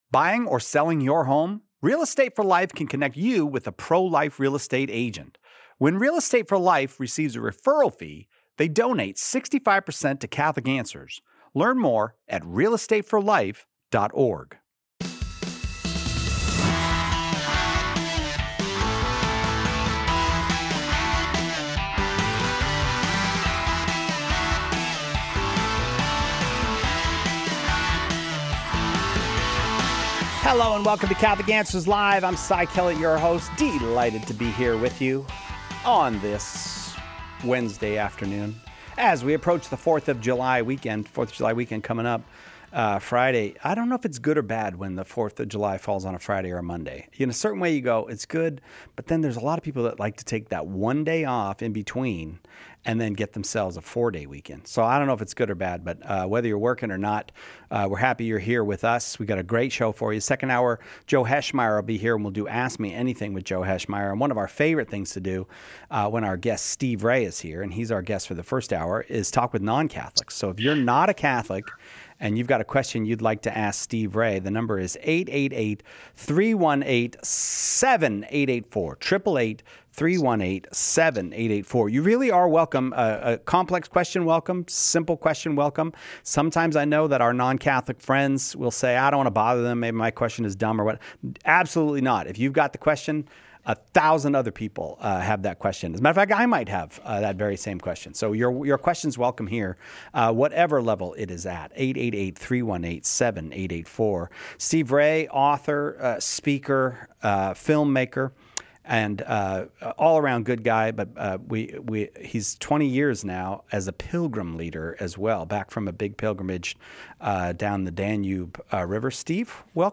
In this episode of Catholic Answers Live , we tackle a wide range of thoughtful questions from callers of various faith backgrounds.